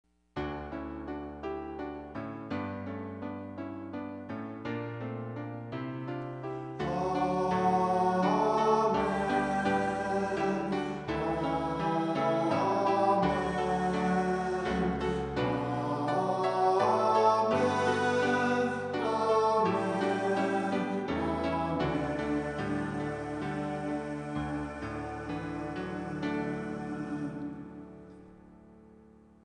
female vocals
Choral    Pop    Home